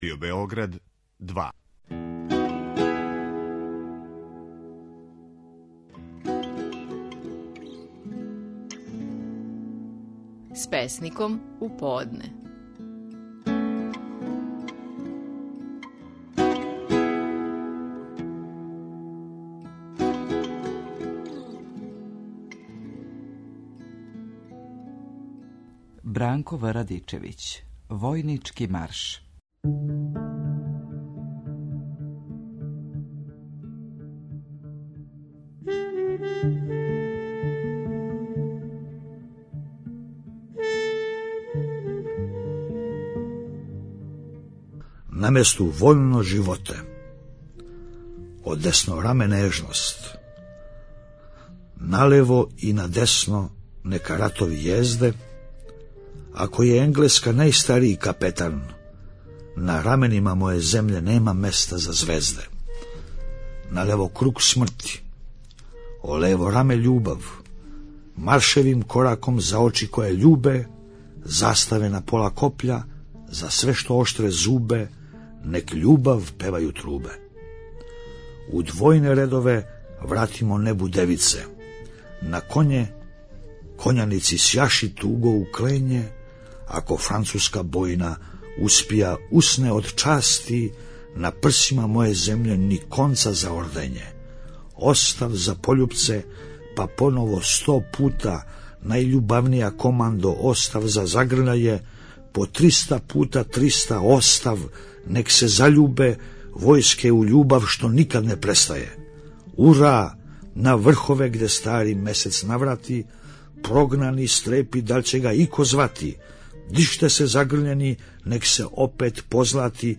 Стихови наших најпознатијих песника, у интерпретацији аутора
Бранко В. Радичевић говори своју песму „Војнички марш".